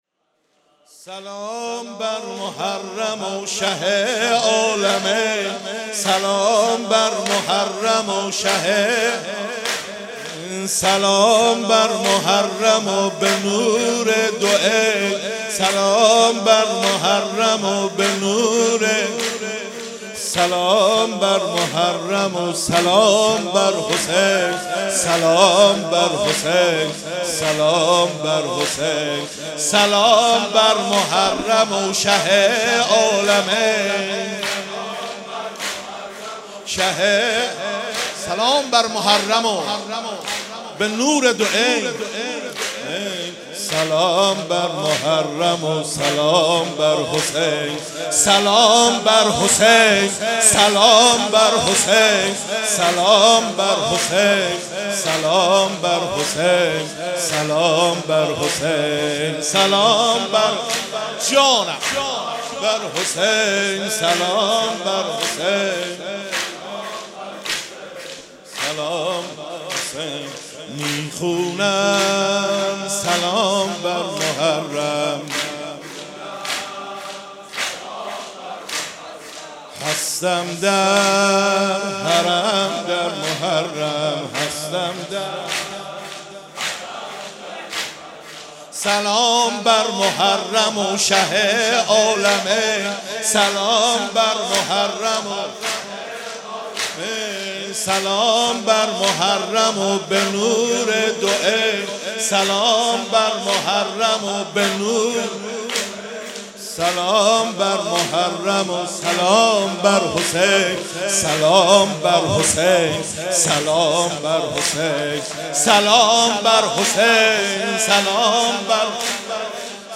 مداحی شب اول محرم
در حسینیه نور البکا- کربلای معلی